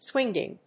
/swíndʒɪŋ(米国英語)/
swingeing.mp3